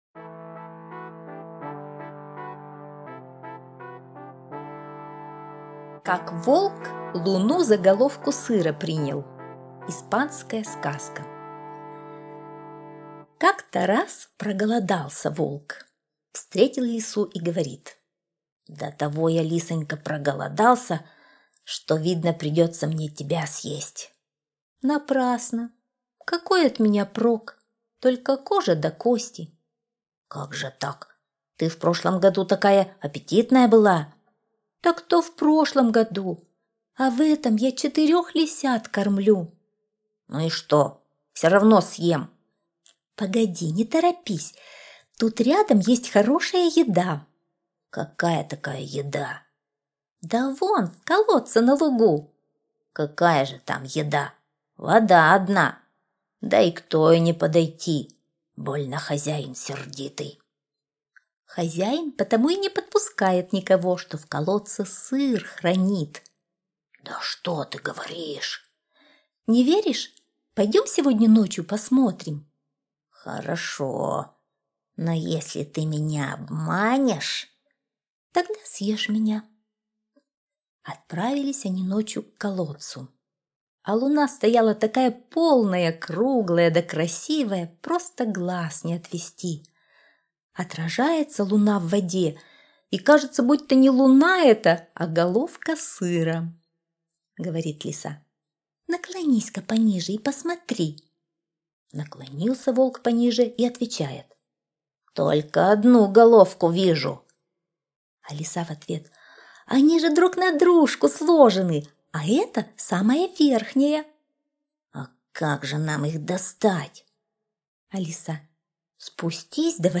Как волк луну за головку сыра принял — испанская аудиосказка. Сказка о том, как голодный волк хотел съесть лису, но лиса сумела его обмануть.